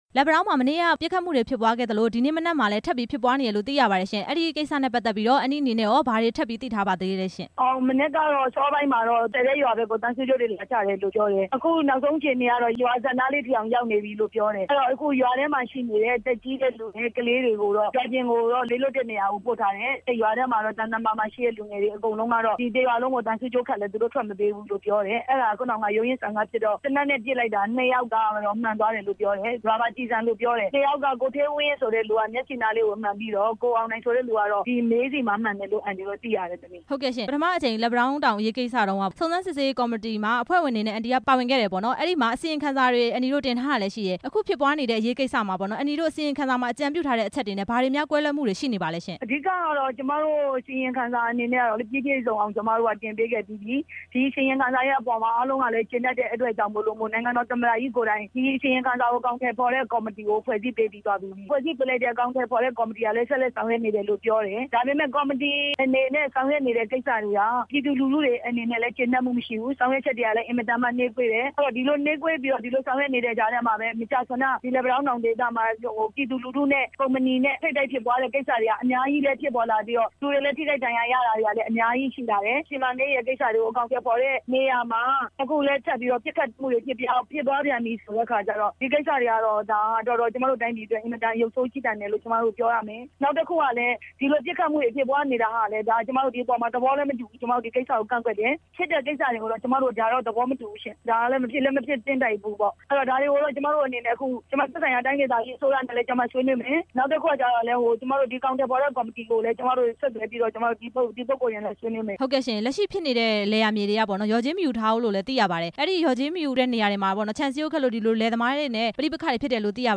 လယ်ယာမြေလျော်ကြေးကိစ္စတွေအတွက် ကျေးရွာ သားတွေနဲ့ ပြေလည်အောင်ညှိနှိုင်းဖို့ ကော်မရှင် အစီရင် ခံစာမှာ ထည့်သွင်းတိုက်တွန်းထားပေမယ့် သက်ဆိုင် ရာတွေက အကောင်အထည်ဖေါ်လိုက်နာခြင်းမရှိဘူးလို့ စုံစမ်းစစ်ဆေးရေးကော်မရှင်အဖွဲ့ဝင် ပြည်သူ့လွှတ်တော် ကိုယ်စားလှယ် ဒေါ်ခင်စန်းလှိုင် က ပြောပါတယ်။